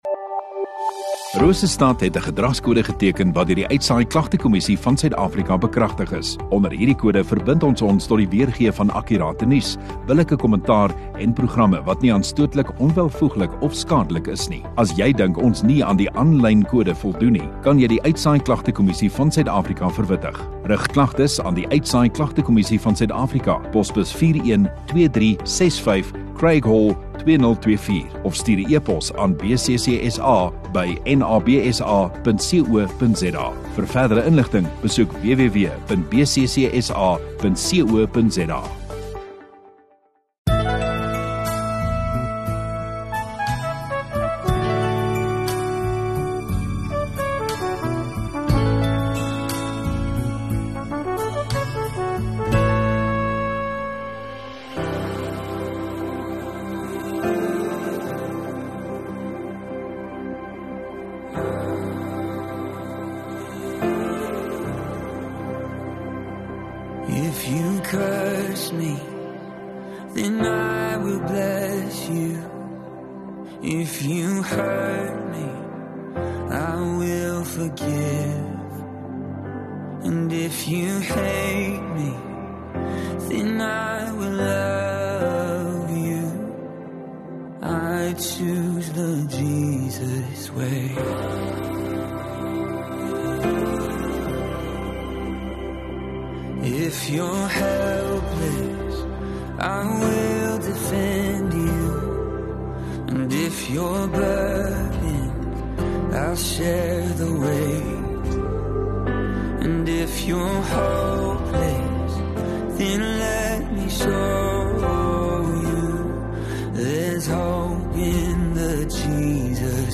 15 Dec Sondagaand Erediens